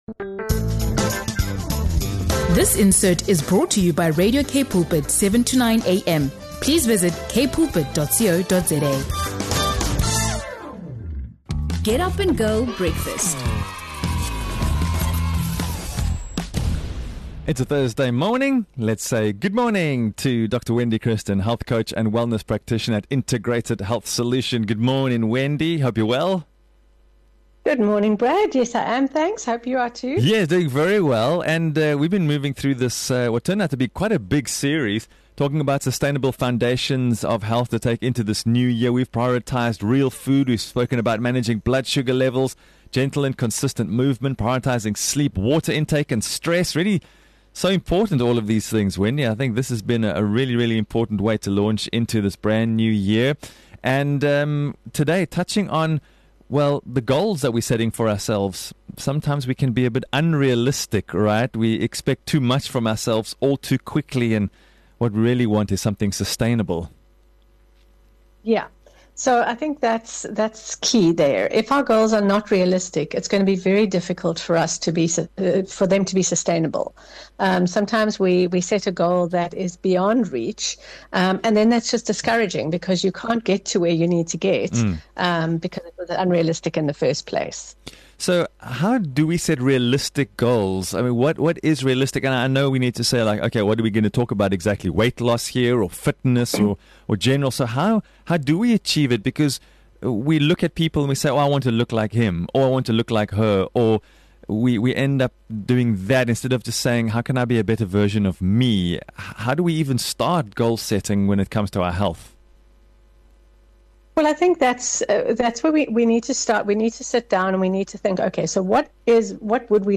As part of an ongoing series on the foundations of health, the conversation covers real food, blood sugar balance, gentle movement, sleep, hydration, stress management, and why patience matters more than perfection.